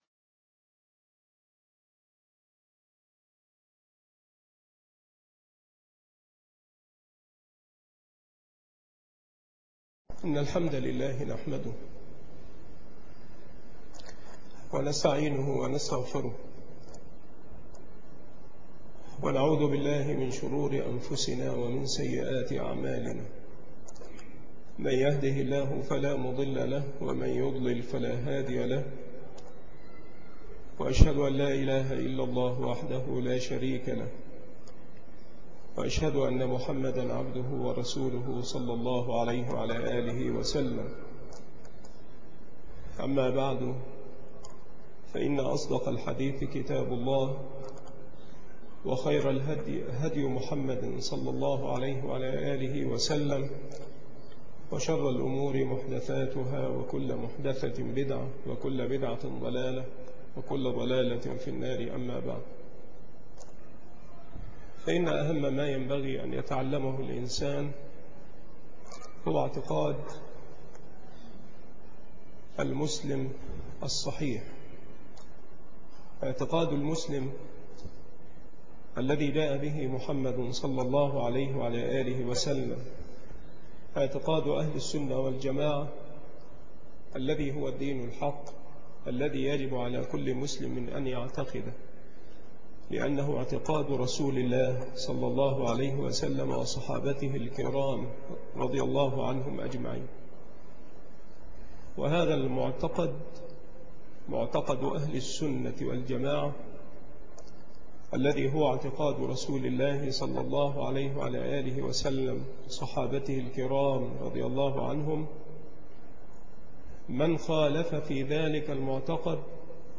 شرح بذل الماعون في فضل الطاعون الدرس 1